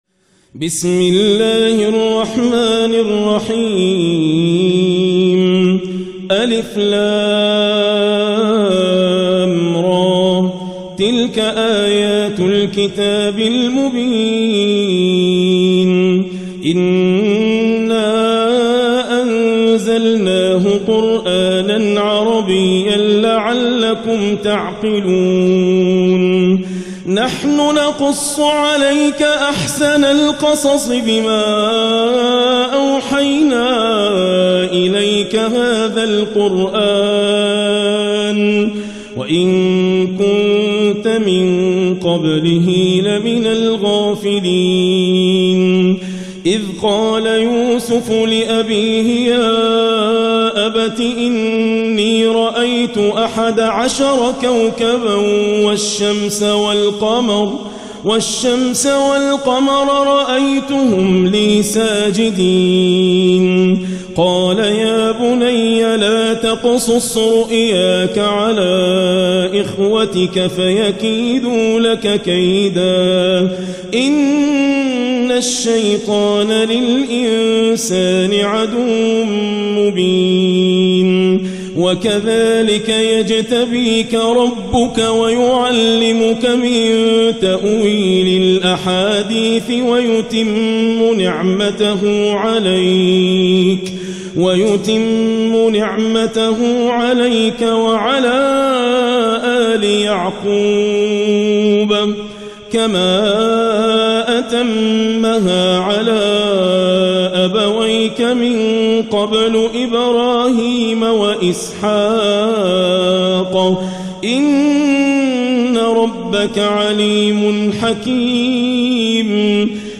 " لقد كان في يوسف و اخوته ايات للسائلين " تلاوة بالحدر